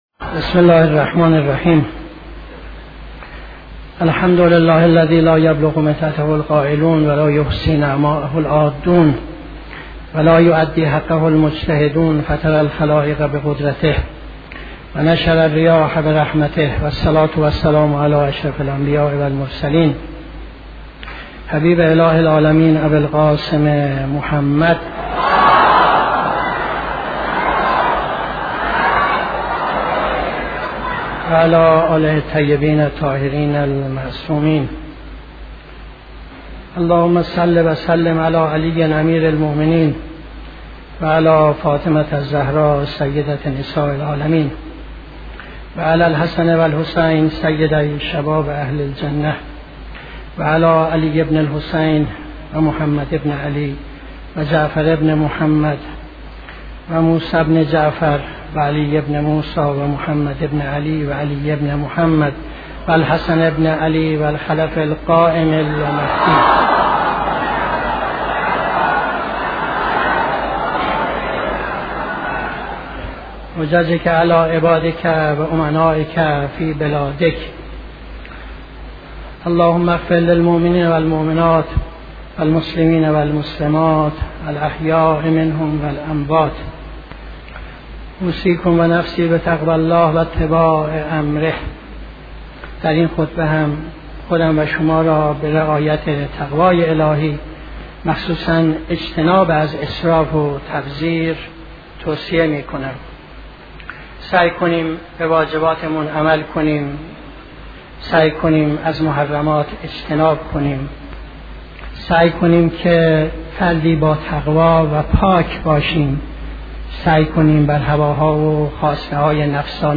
خطبه دوم نماز جمعه 30-09-75